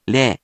If you press the 「▶」button on the virtual sound player, QUIZBO™ will read the random hiragana to you.
In romaji, 「れ」 is transliterated as 「re」which sounds sort of like 「lay」